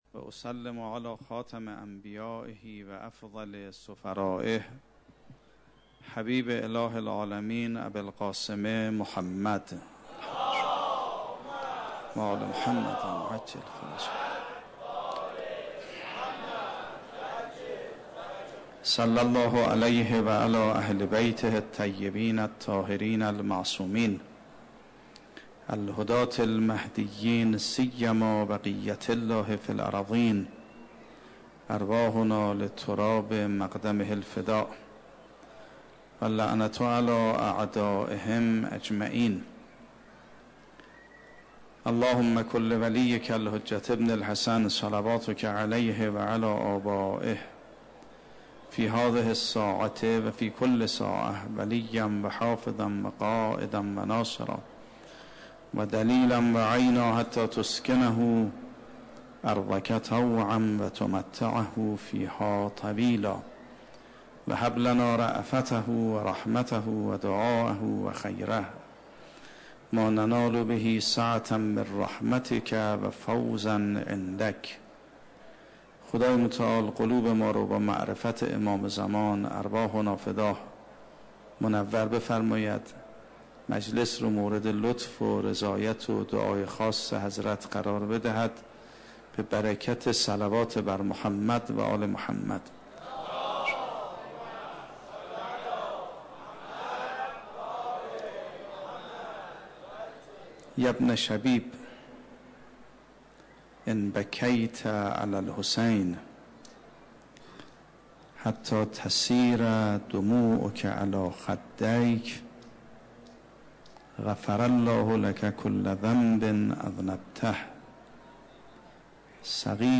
28 شهریور 96 - مسجد جمکران - سخنرانی